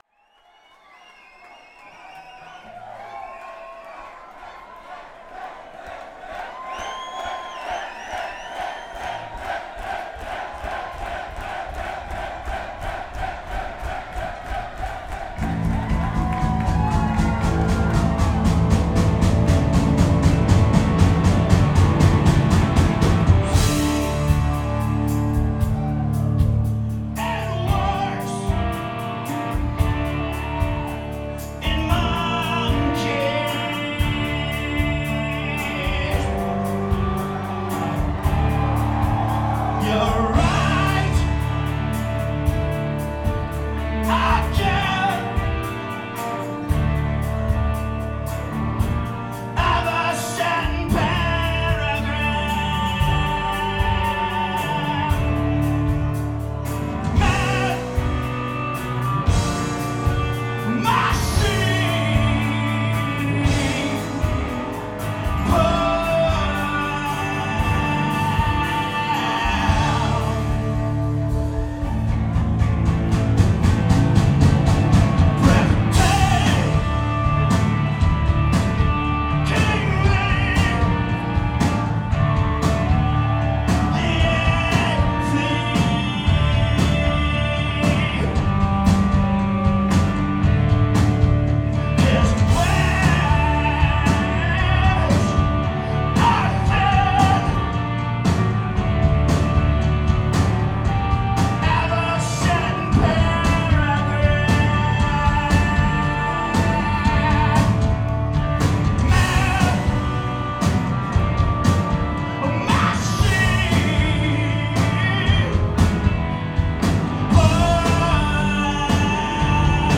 (5th Live Performance)